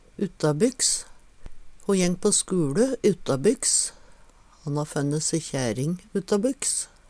DIALEKTORD PÅ NORMERT NORSK uttabykks heimanfrå, frå ein annan stad Eksempel på bruk Ho jeng på skuLe uttabykks.